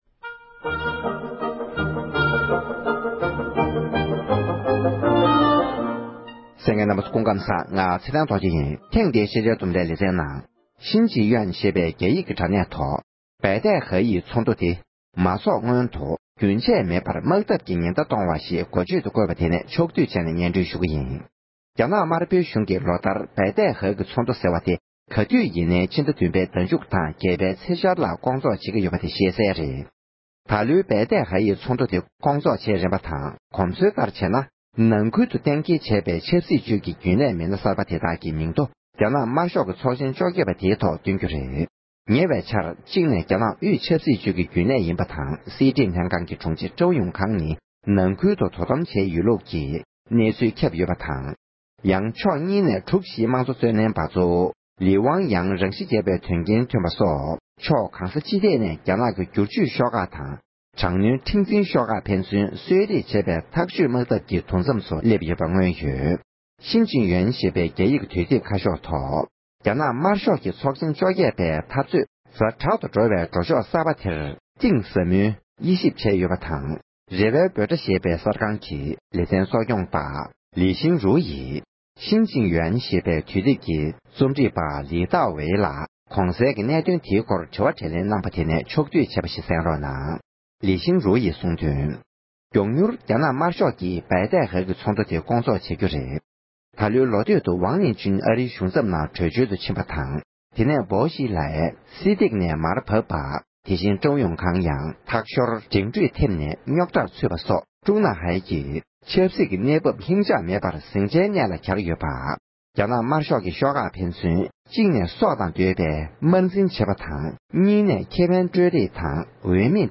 རྒྱ་ནག་དམར་ཤོག་གི་ཚོགས་ཆེན་བཅོ་༡༨་ཐོག་དབུས་ཆབ་སྲིད་ཅུས་ཀྱི་རྒྱུན་ལས་ནང་མི་སྣ་སུ་དང་སུ་སླེབས་སྲིད་པའི་དྲི་བ་དྲི་ལན་གནང་བ།